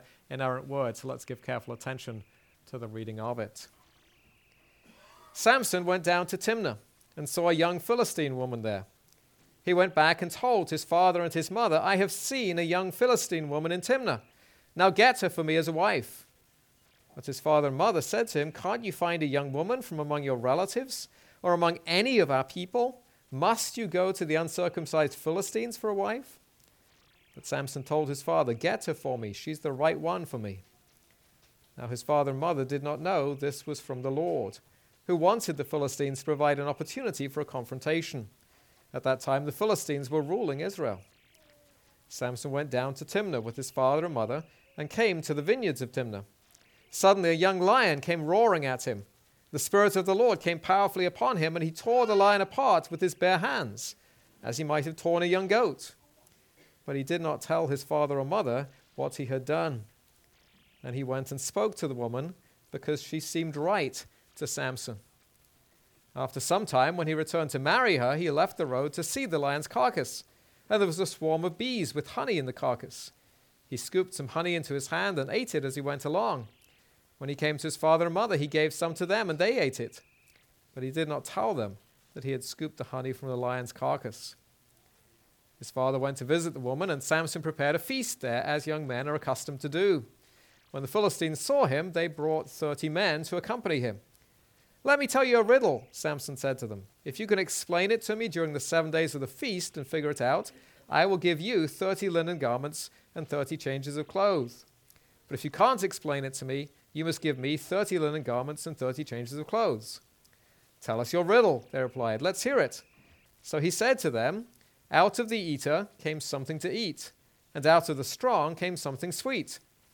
This is a sermon on Judges 14.